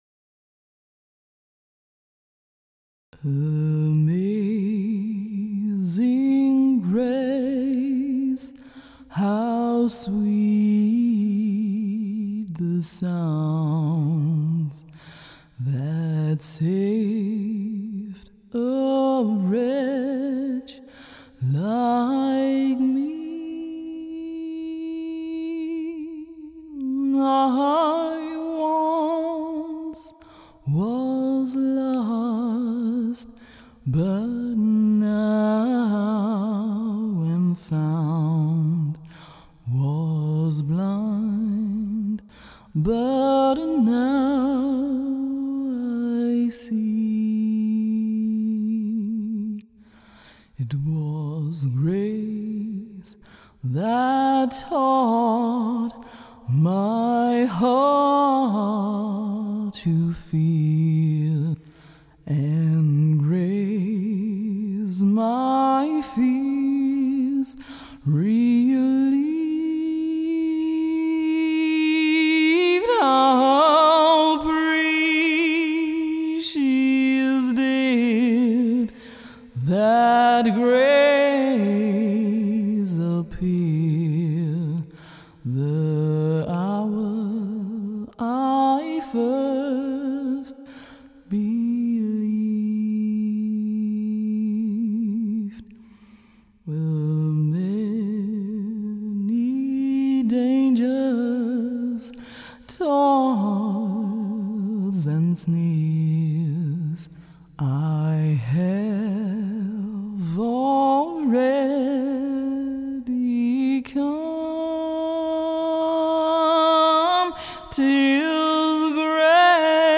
*Beispiel für Live-Musik:
Amazing Grace (Erstaunliche Gnade) ist ein englischsprachiges geistliches Lied, das zu den beliebtesten Kirchenliedern der Welt zählt. 1972 kam es in einer Version der Royal Scots Dragoon Guards an die Spitze der britischen Charts.